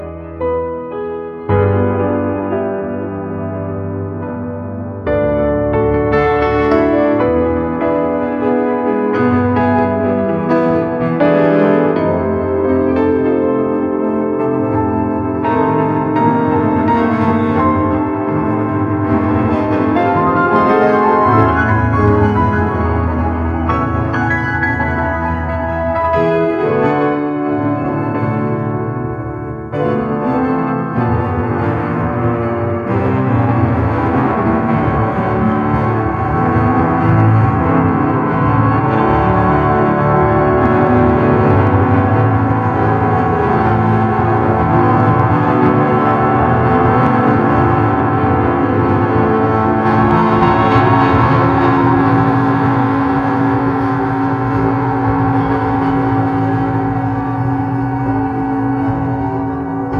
"a piano solo "
"prompt": "a piano solo ",